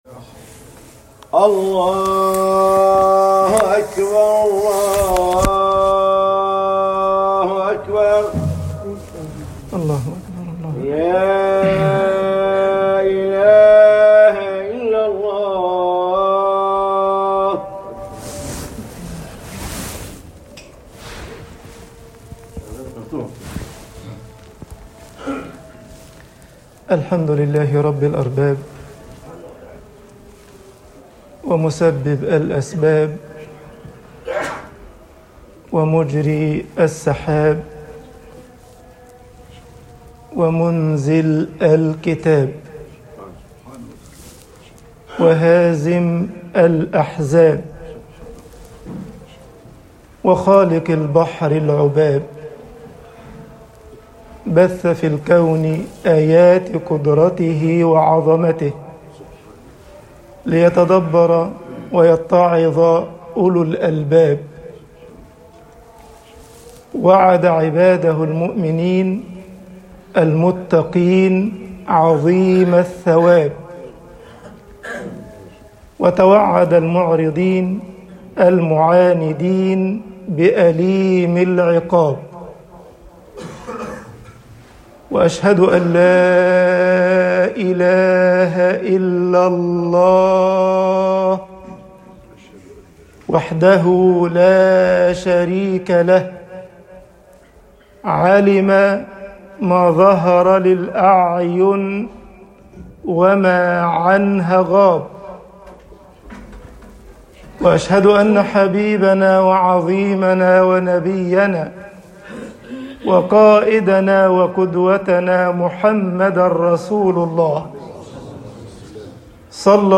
خطب الجمعة - مصر أُصول النِعَمِ الدُنْيَويَة